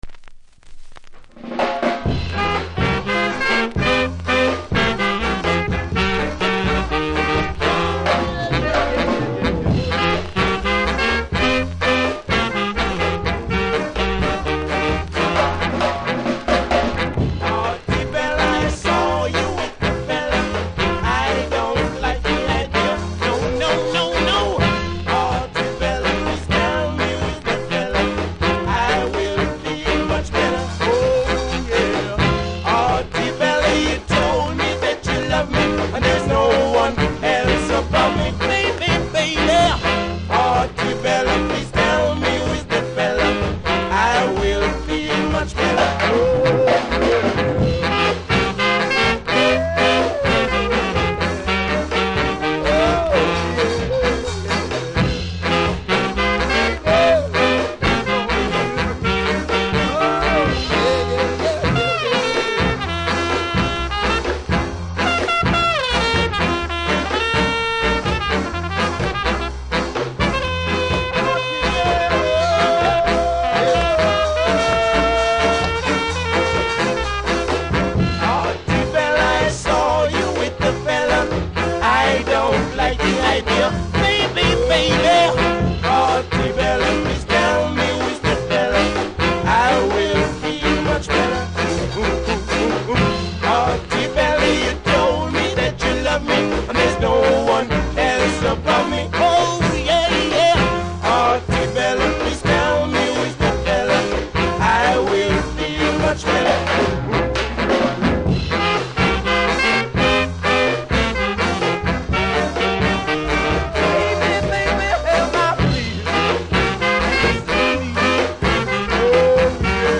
見た目は良くないですが音はキズほど感じないので試聴で確認下さい
少し歪みありますがプレイは問題無いレベル